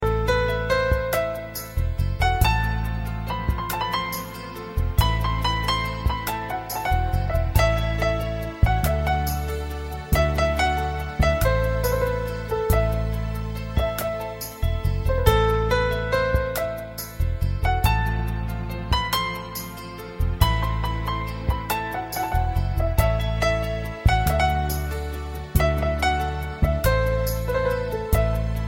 Ringtones Mp3